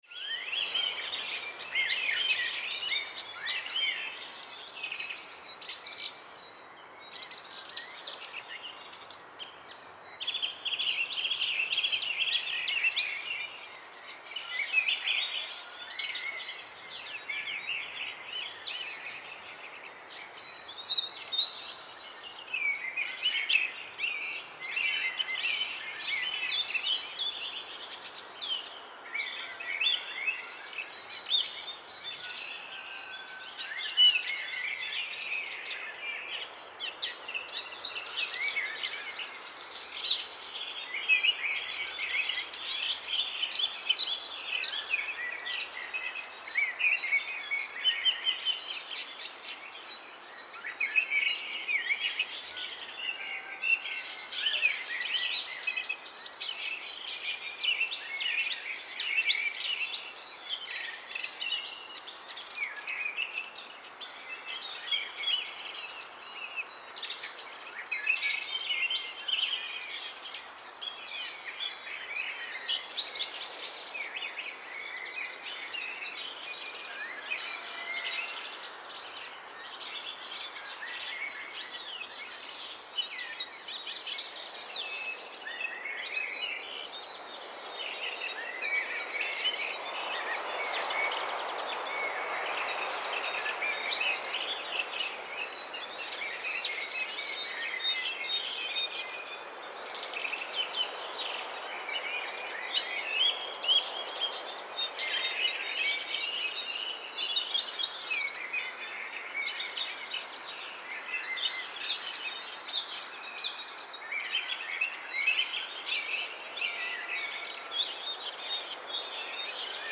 Animal Sounds / Bird Sounds / Sound Effects 23 Mar, 2026 Morning Bird Sound Read more & Download...
Morning-birds-chirping-sound-effect.mp3